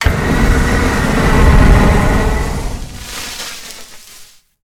flamethrower_shot_06.wav